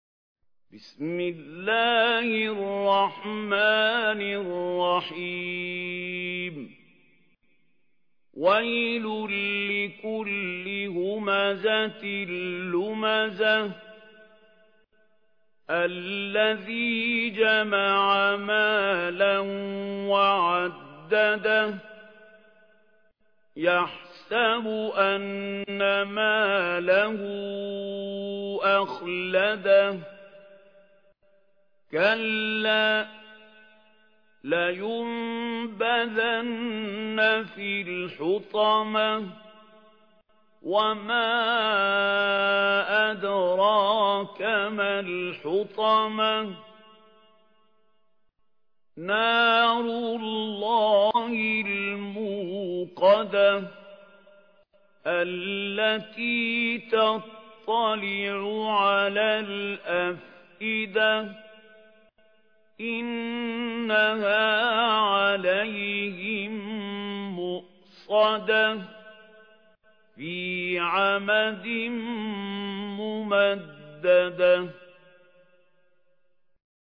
ترتيل